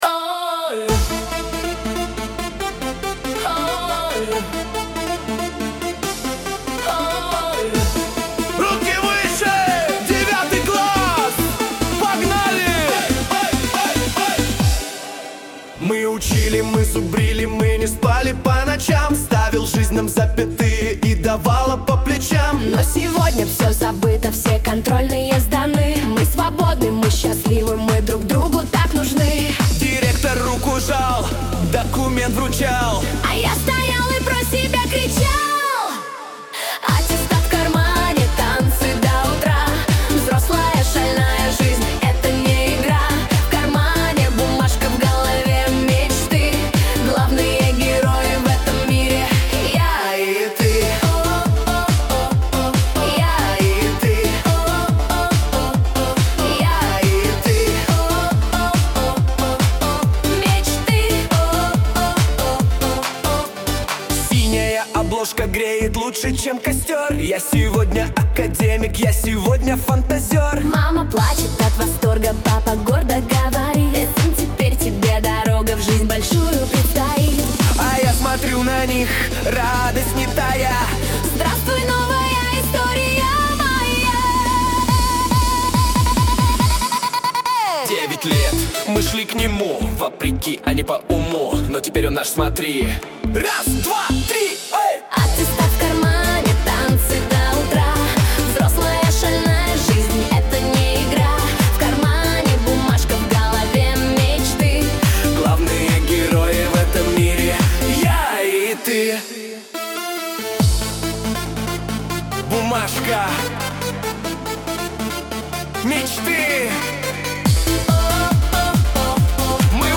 Танцевальный отрыв, свобода и драйв.
💿 Вайб: Рейв 90-х